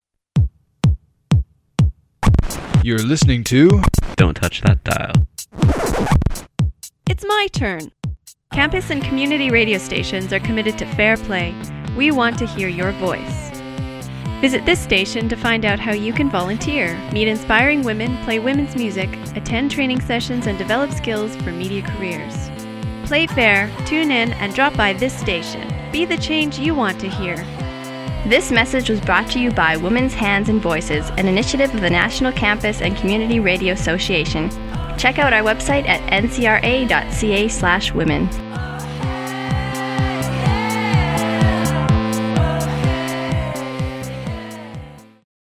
MP3s of recruitment ads for women